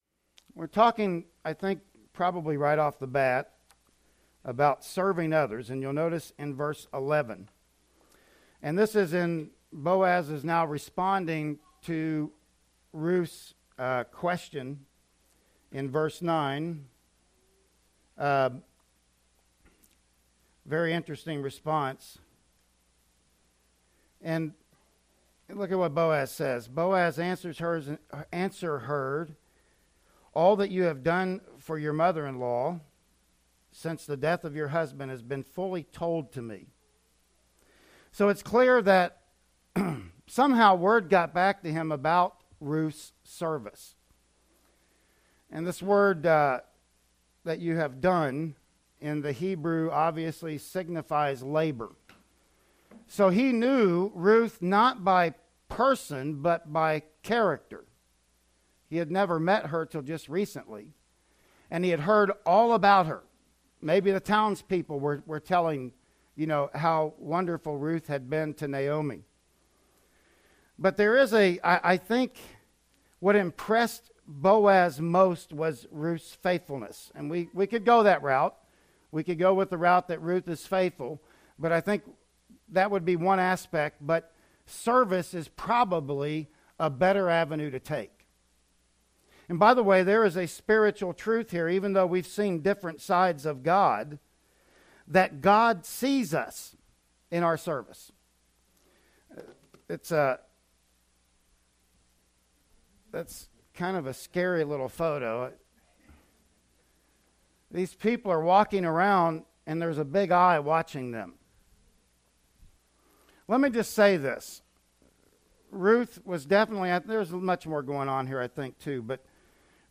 "Ruth 2:11-13" Service Type: Sunday Morning Worship Service Bible Text